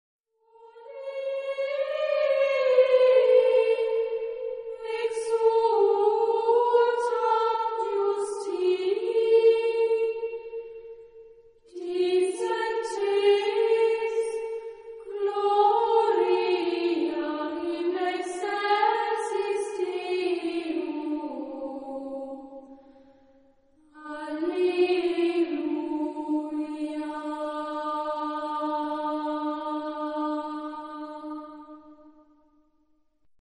Genre-Style-Forme : Chant de Noël ; Sacré
Caractère de la pièce : mélodieux ; serein ; cantabile ; binaire ; calme
Type de choeur : unisson  (1 voix unisson )
Tonalité : ré mode de la
Sources musicologiques : Magnificat-Antiphon, Gregorien